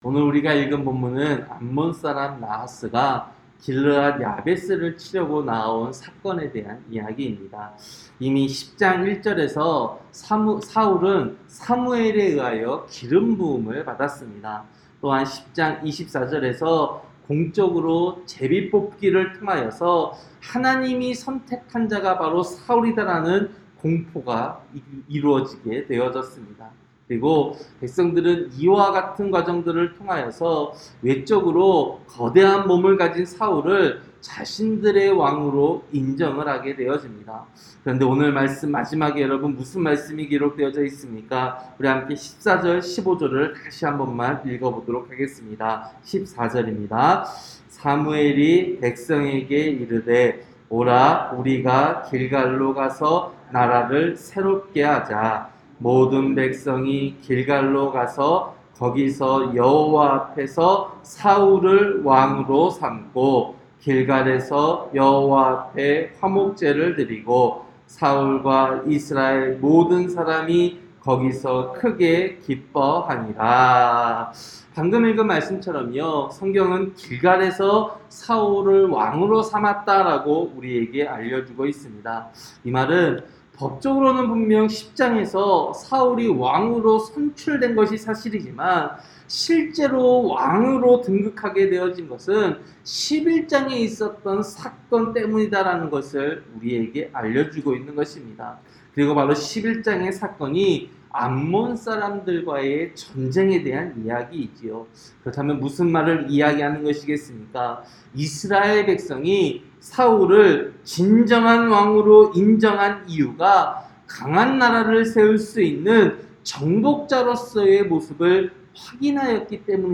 새벽설교-사무엘상 11장